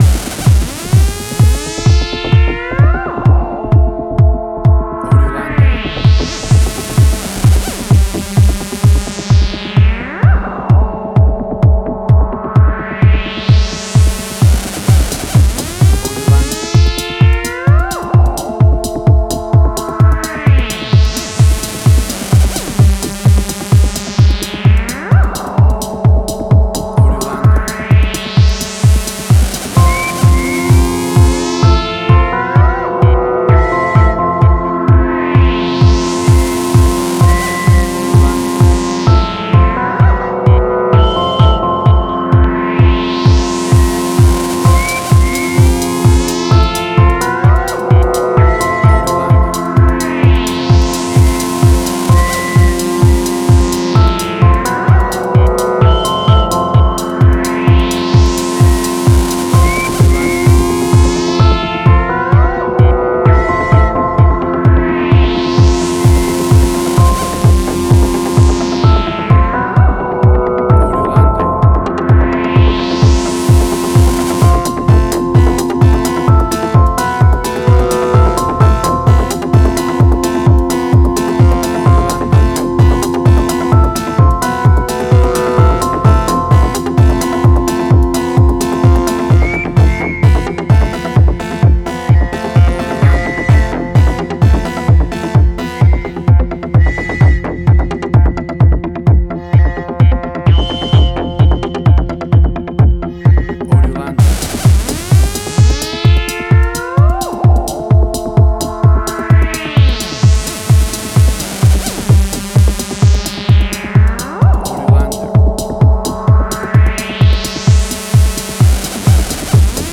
House.
Tempo (BPM): 128